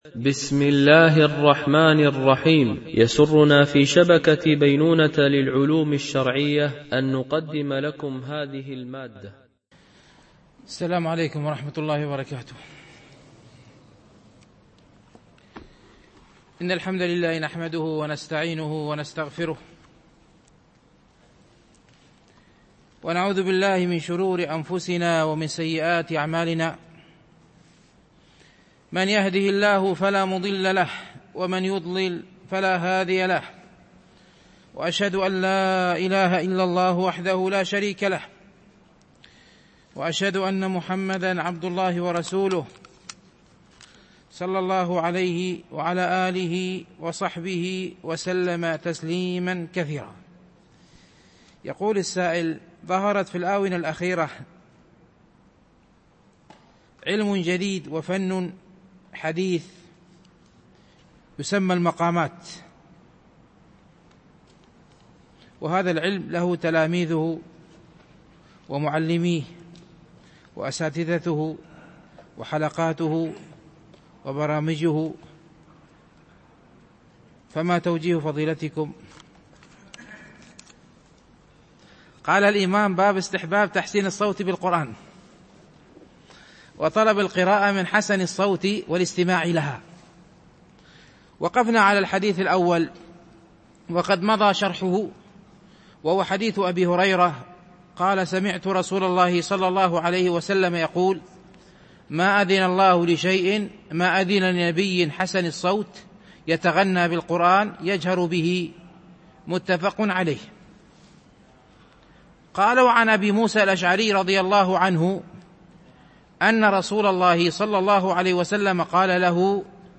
شرح رياض الصالحين – الدرس 262 ( الحديث 1012 – 1015 )